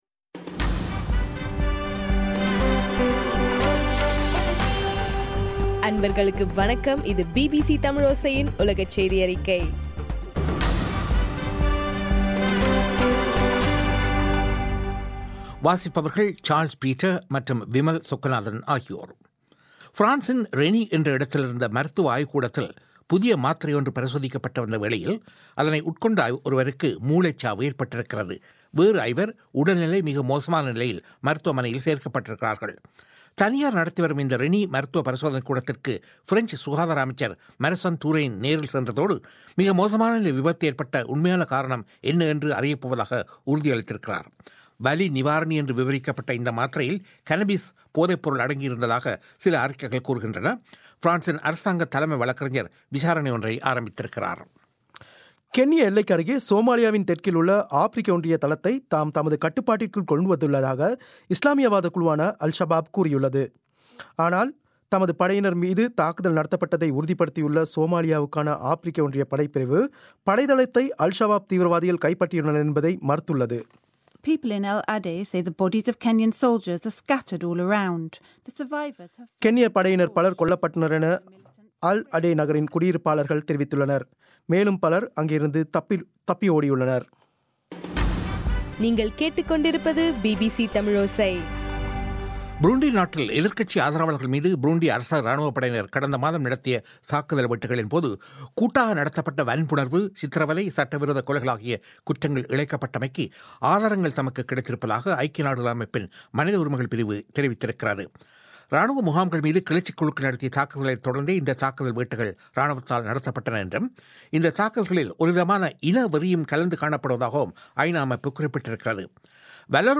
ஜனவரி 15, 2016 பிபிசி தமிழோசையின் உலகச் செய்திகள்